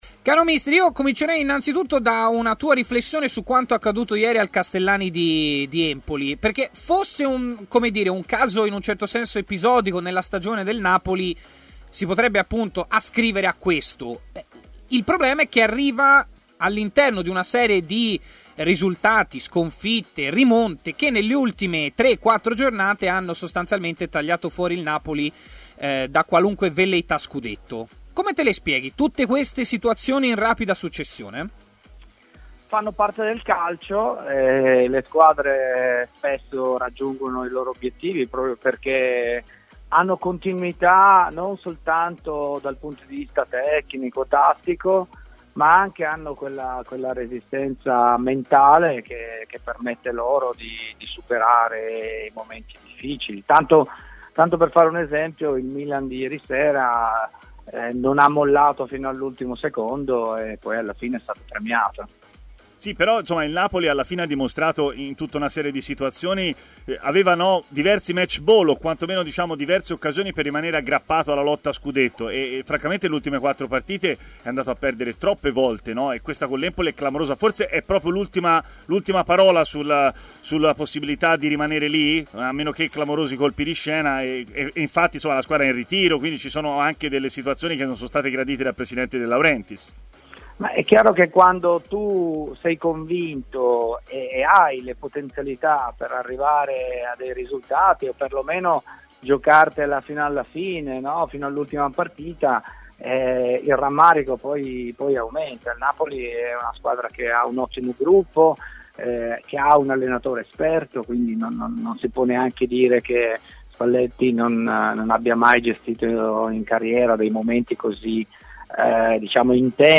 L'allenatore Giancarlo Camolese ha così parlato durante Stadio Aperto, trasmissione di TMW Radio iniziando dal giro a vuoto di ieri del Napoli